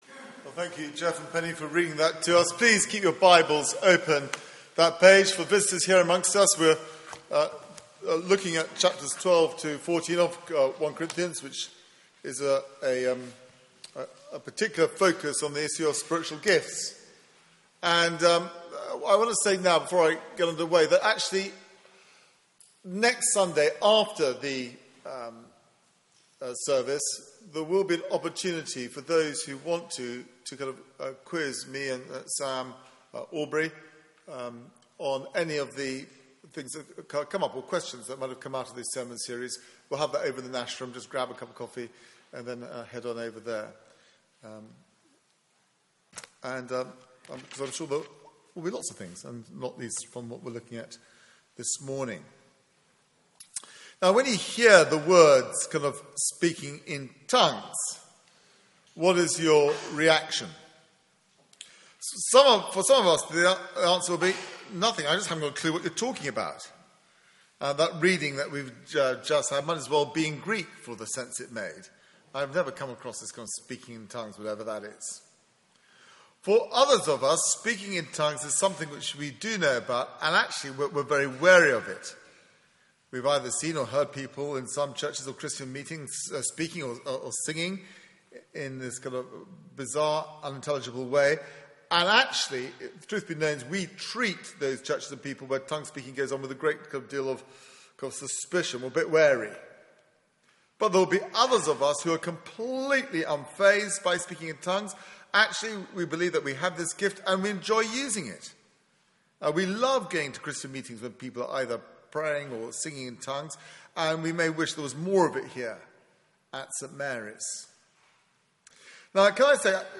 Media for 9:15am Service on Sun 15th Nov 2015
Series: A Church with Issues Theme: Prophecy and Tongues Sermon